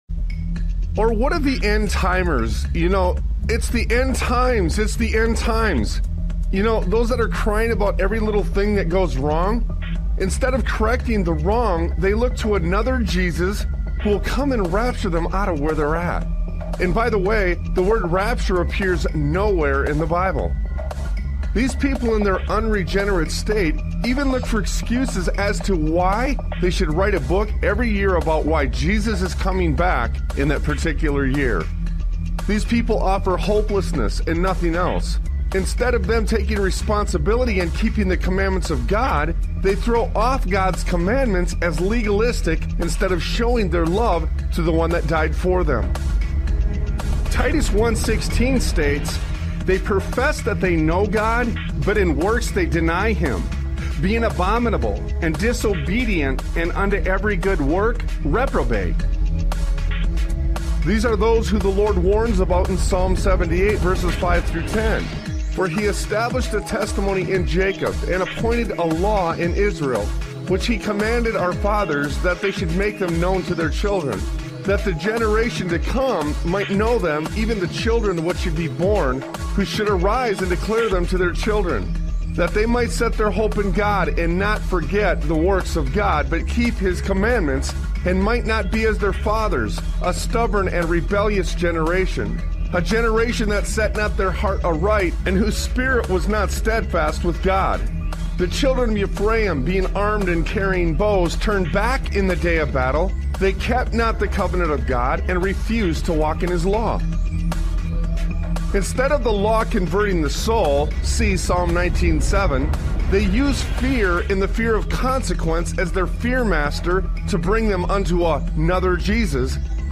Talk Show Episode, Audio Podcast, Sons of Liberty Radio and Raptured From Responsibility Is What The Heretics Sell on , show guests , about Raptured From Responsibility Is What The Heretics Sell, categorized as Education,History,Military,News,Politics & Government,Religion,Christianity,Society and Culture,Theory & Conspiracy